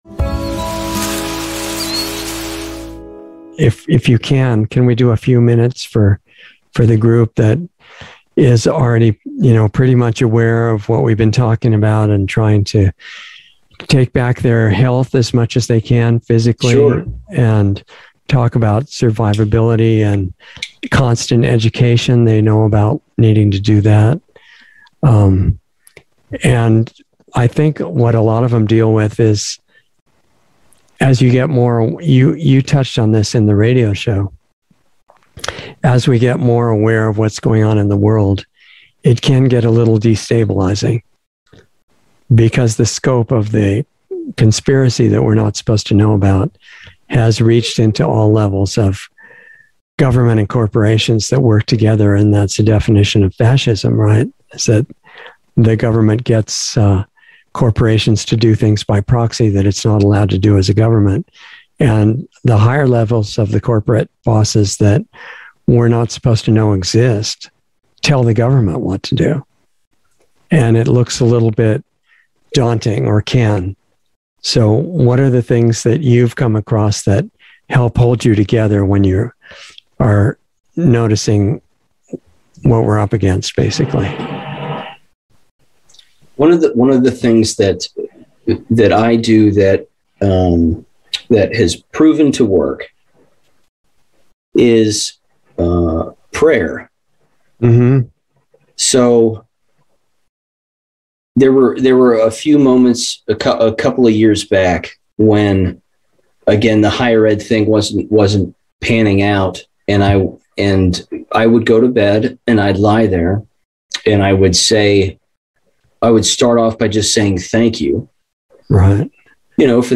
Insider Interview 8/30/21